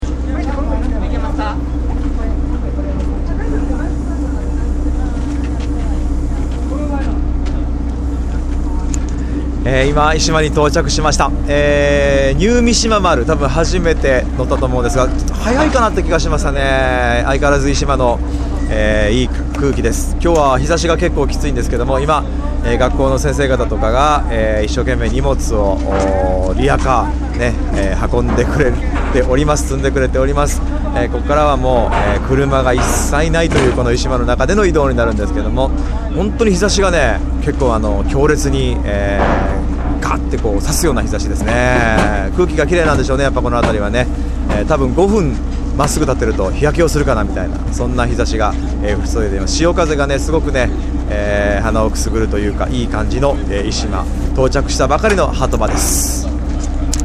ishimaharbor.mp3